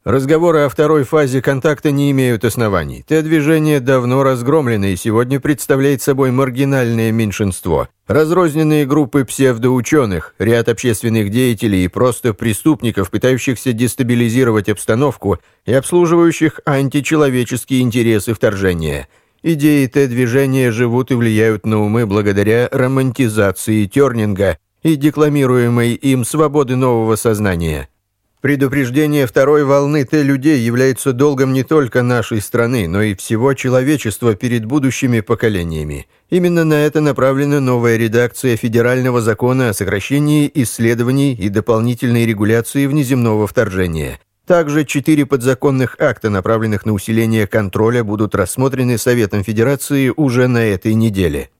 Микрофон: AKG C3000
Подача может быть строгой, жесткой, романтичной, шутливой, любой, которой требует текст.
У меня своя домашняя студия, поэтому доступ к микрофону возможен практически в любое время.